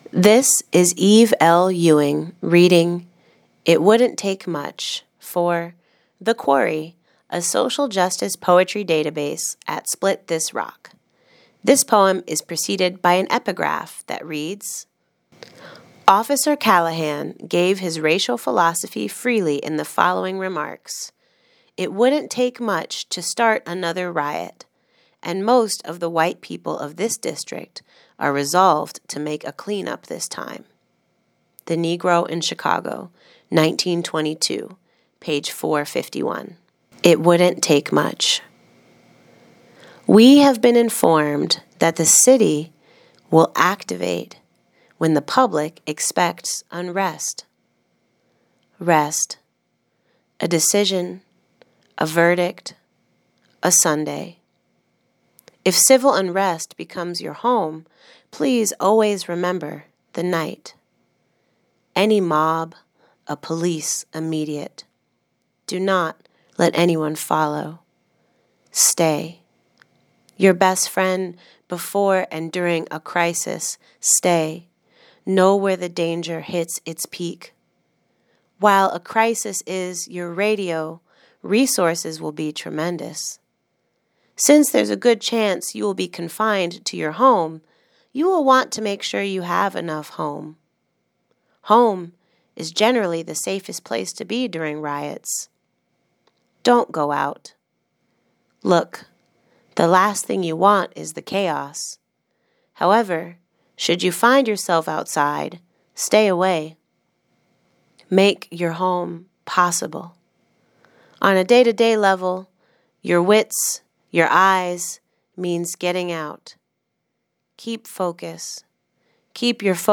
Listen as Eve L. Ewing reads "it wouldn't take much."